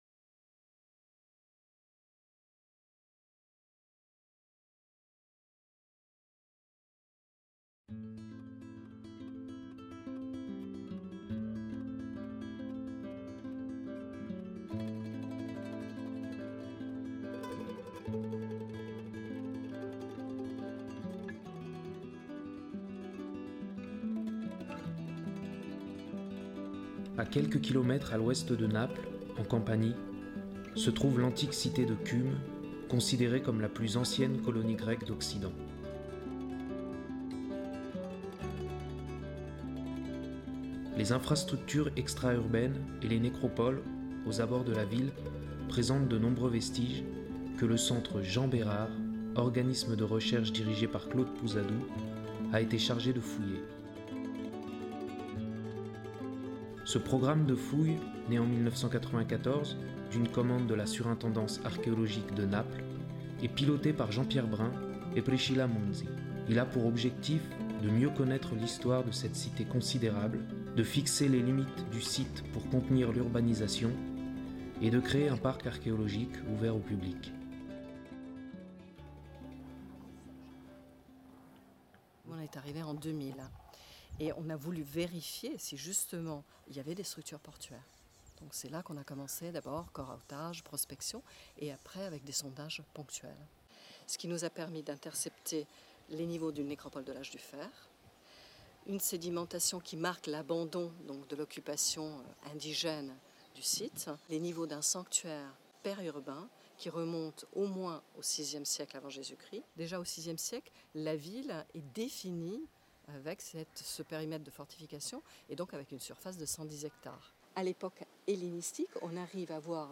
Reportage effectué sur le site de Cumes (Cuma), à quelques kilomètres à l'ouest de Naples, en Campanie